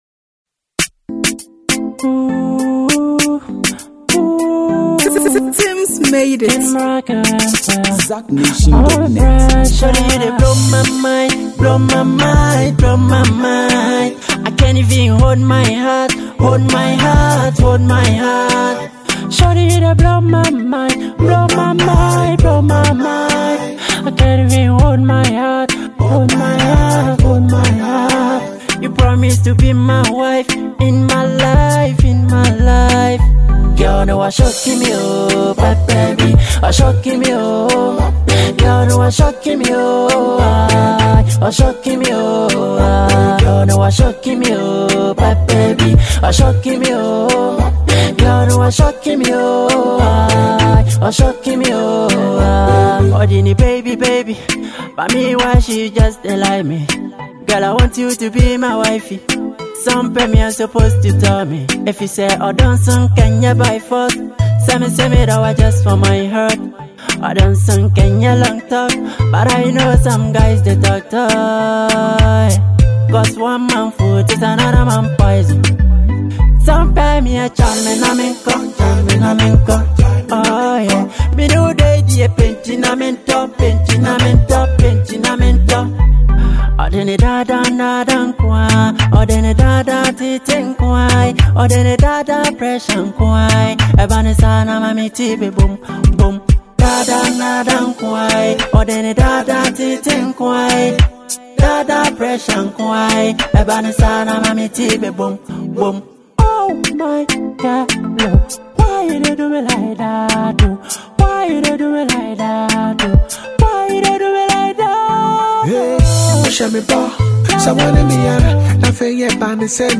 With its infectious energy and memorable hooks